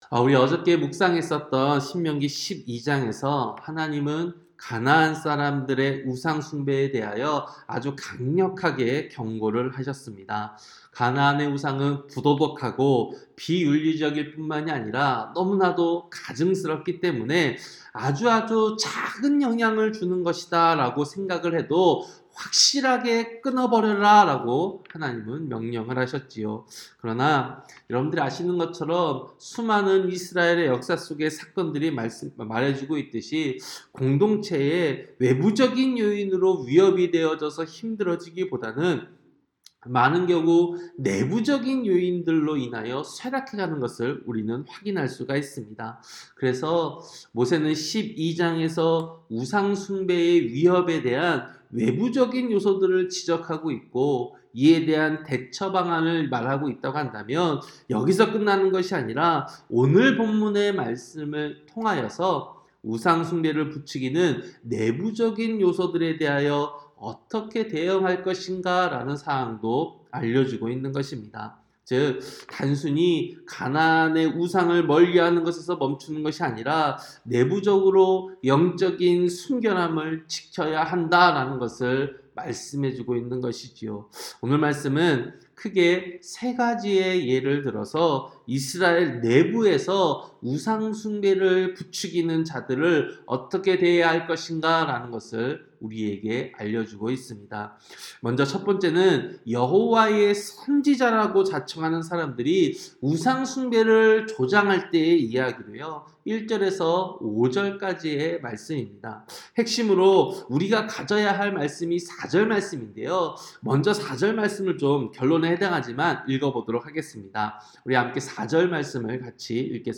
새벽설교-신명기 13장